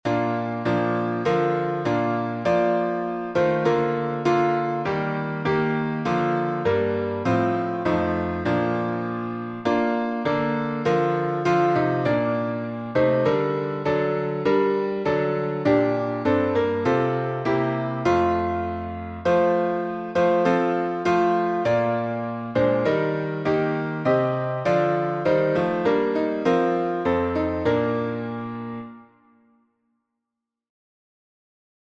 Key signature: B flat major (2 flats) Time signature: 4/4